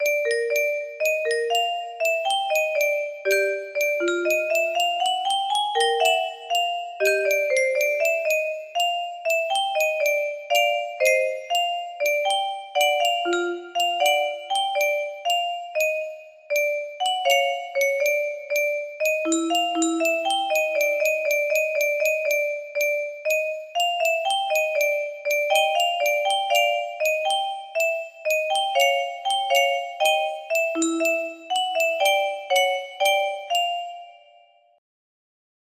Unknown Artist - Untitled music box melody
Grand Illusions 30 (F scale)